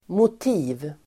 Ladda ner uttalet
Uttal: [mot'i:v]